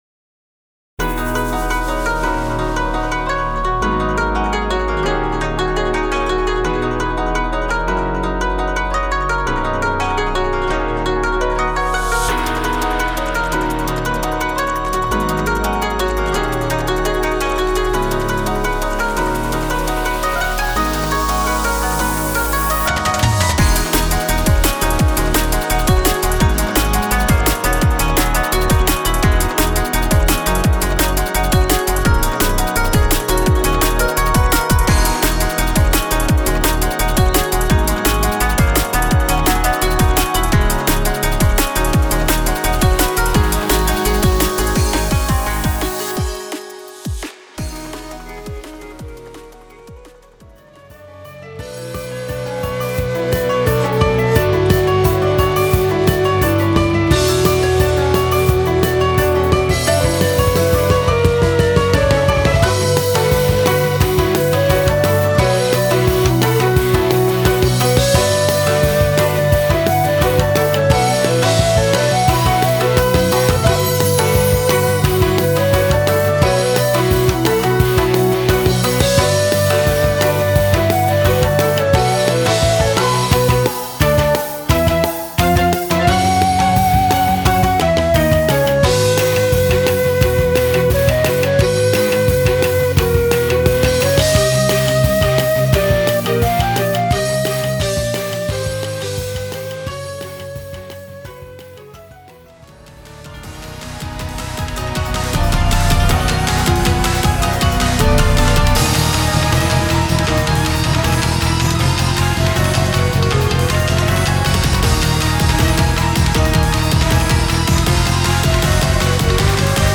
This is a disc arranged with various Japanese-style tastes.